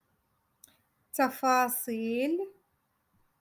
Moroccan Dialect- Rotation Three- Lesson Eight